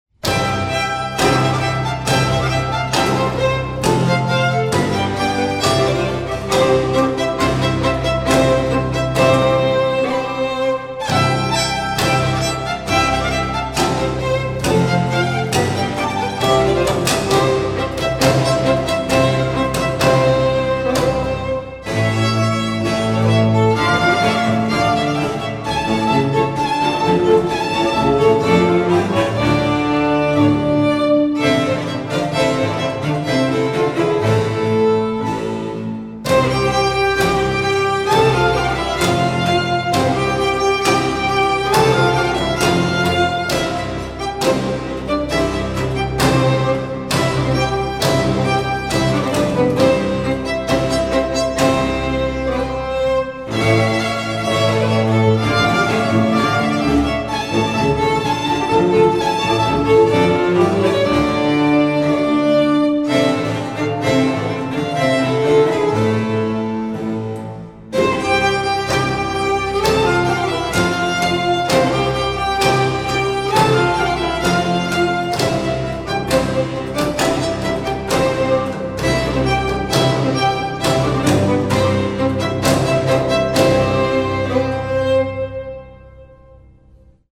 * J. J. Fux / Concentus musico-instrumentalis : Baroque string quintet, harpsichord / positive organ.